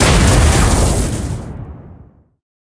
enrager_ulti_hit_01.ogg